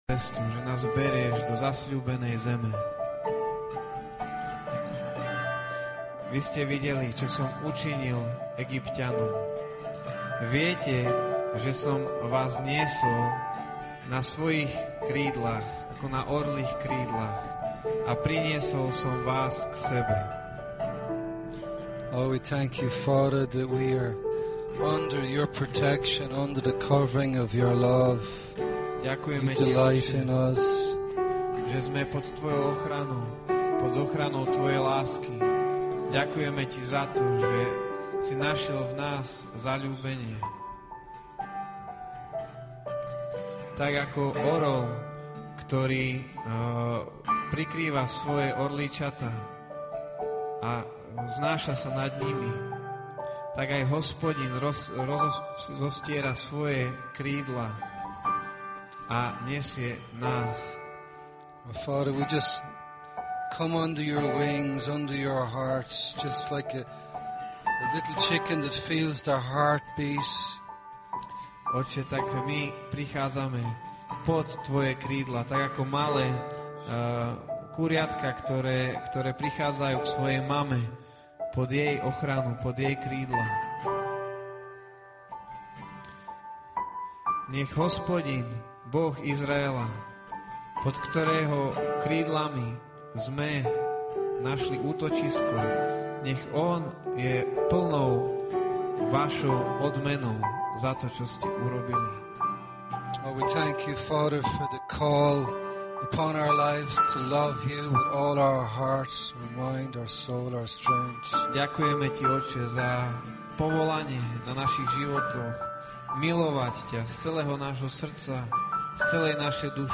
Stiahnuť Mp3 Speaker